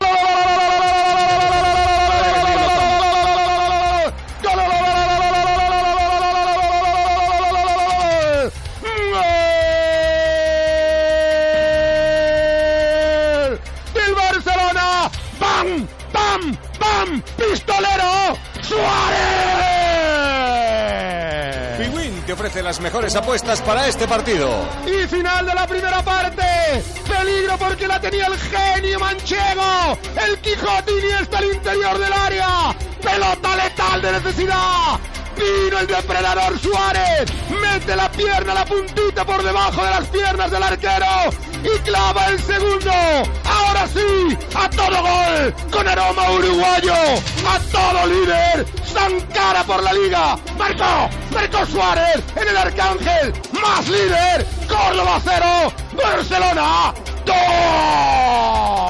Transmissió del partit de lliga de la primera divisió masculina de futbol entre el Córdoba i el Futbol Club Barcelona.
Narració del gol de Luis Suárez.
Esportiu